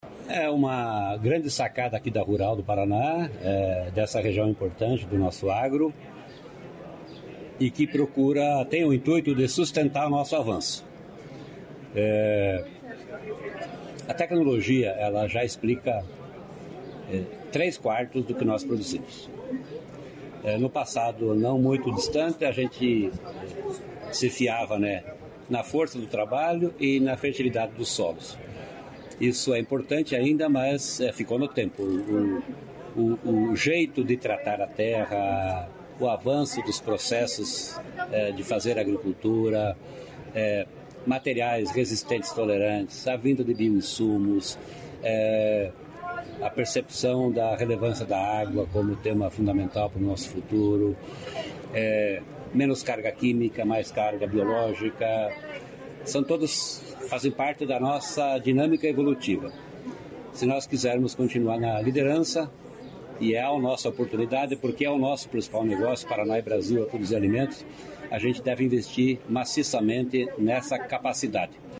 Sonora do secretário da Agriculta e do Abastecimento, Norberto Ortigara, sobre exemplo de inovação e sustentabilidade no Paraná